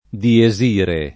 dies irae [lat. d&eS &re] locuz. m. — con D- maiusc., Dies irae, come tit. (m., in it.) d’una sequenza liturgica — come s. m. d’uso fam., con sign. fig. («giorno della resa dei conti»), anche diesire [